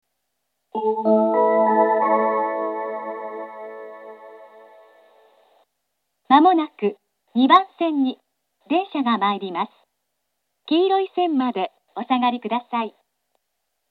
接近放送があり、「東海道型」の放送が使用されています。
２番線接近放送 女声の放送です。